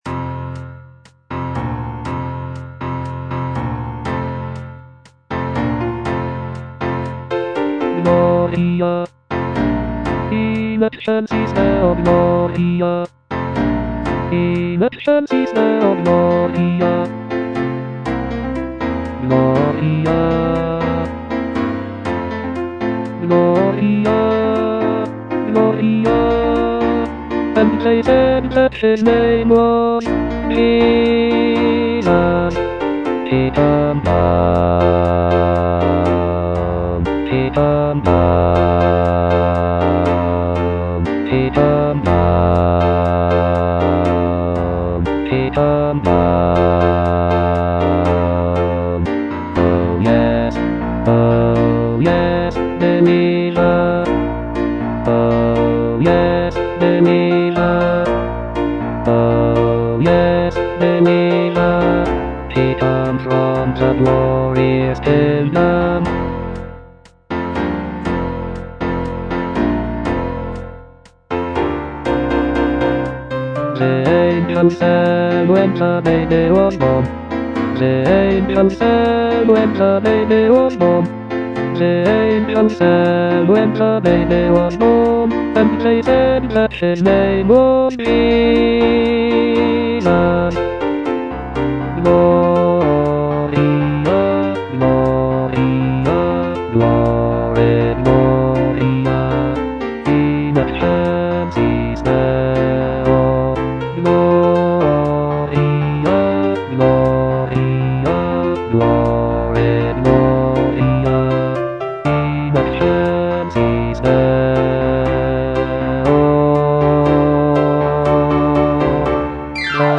Bass (Voice with metronome)
" set to a lively calypso rhythm.